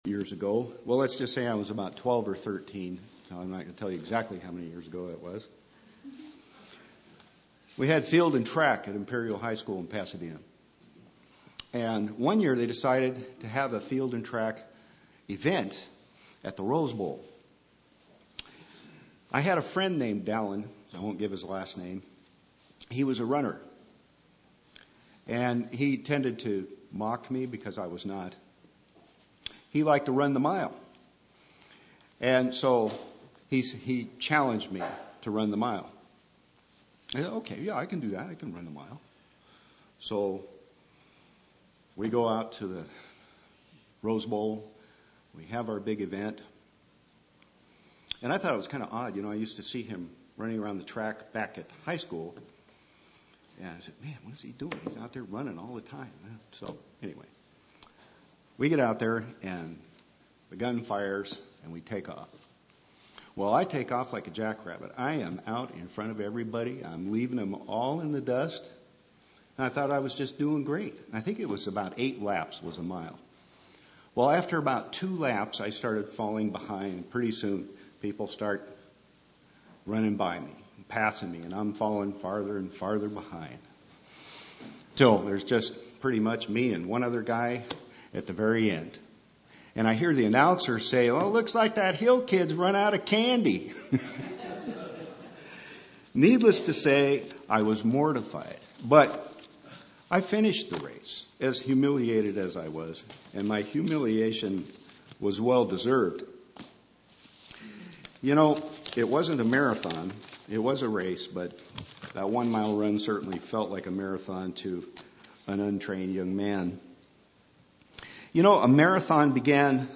Sermons
Given in Eureka, CA San Jose, CA